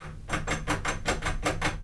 sfx_action_doorknob_01.wav